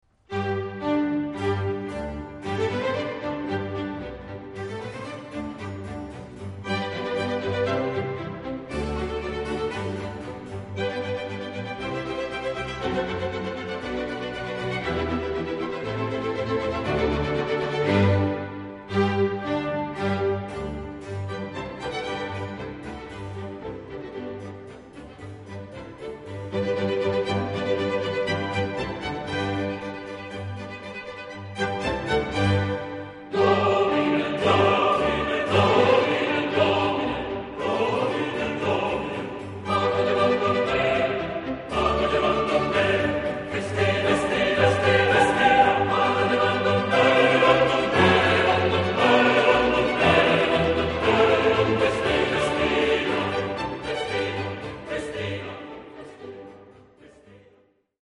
Genre-Style-Forme : Sacré ; Baroque ; Motet
Solistes : Soprano (1)  (1 soliste(s))
Instrumentation : Orchestre
Tonalité : sol majeur